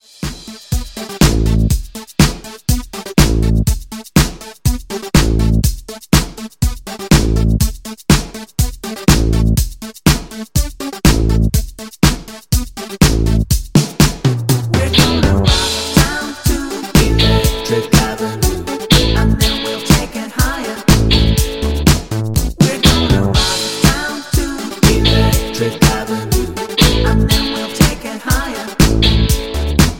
MPEG 1 Layer 3 (Stereo)
Backing track Karaoke
Pop, 1980s